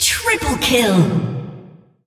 triple-kill-2.mp3